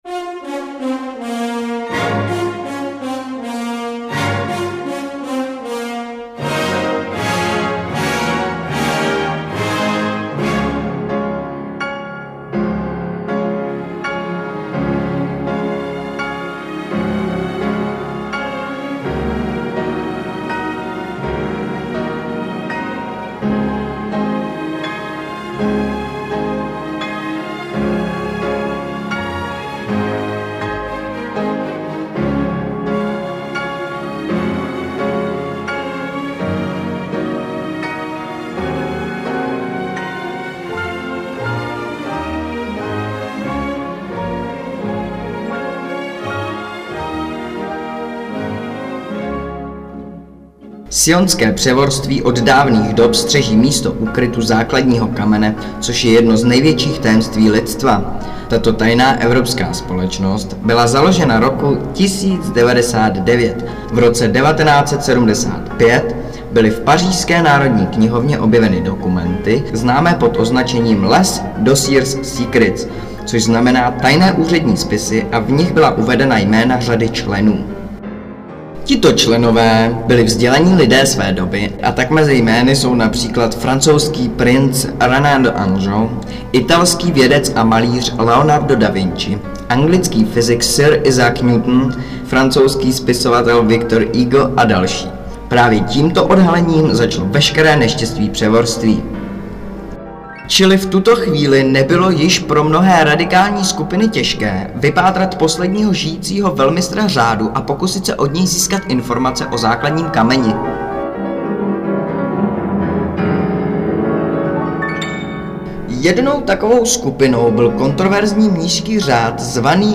Vypravěč -
nahráno na CD a podbarveno hudbou